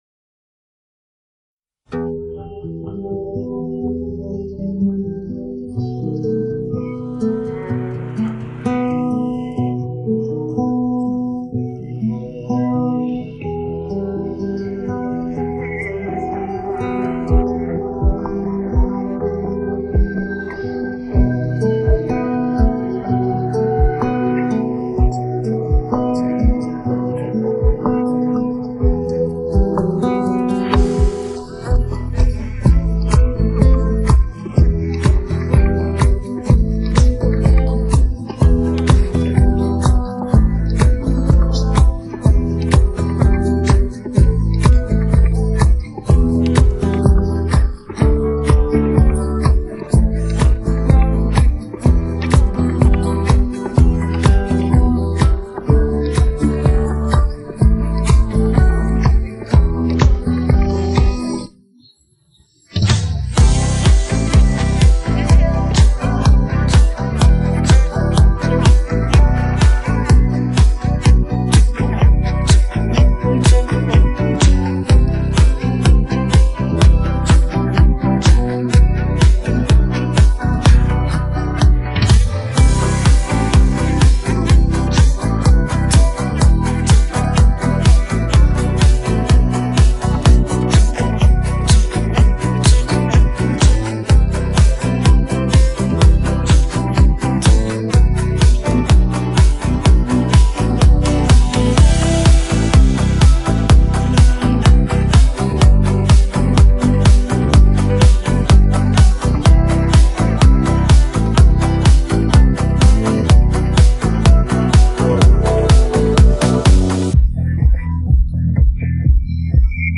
پخش نسخه بیکلام
download-cloud دانلود نسخه بی کلام (KARAOKE)